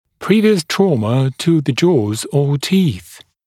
[‘priːvɪəs ‘trɔːmə tə ðə ʤɔːz ɔː tiːθ][‘при:виэс ‘тро:мэ ту зэ джо:з о: ти:с]предыдущие травмы челюстей или зубов, травма челюстей или зубов в анамнезе